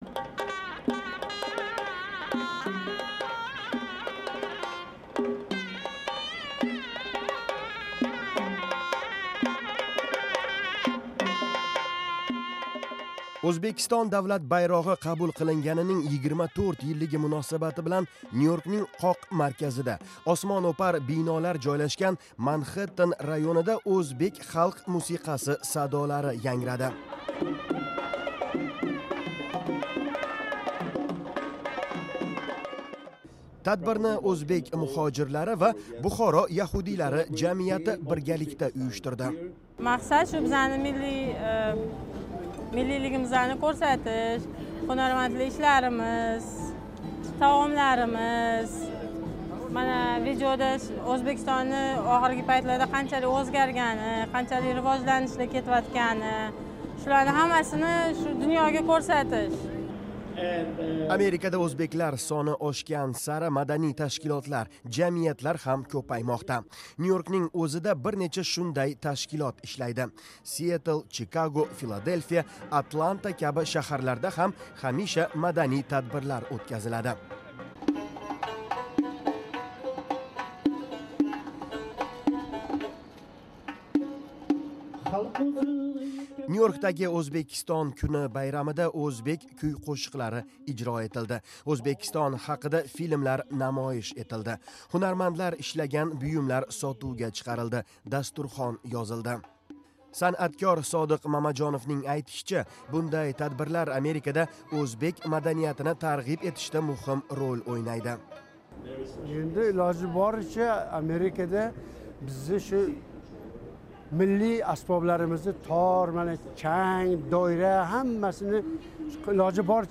O’zbekiston davlat bayrog’i qabul qilinganining 24 yilligi munosabati bilan Nyu-Yorkning qoq markazida, osmono’par binolar joylashgan Manxetten rayonida o’zbek xalq musiqasi sadolari yangradi.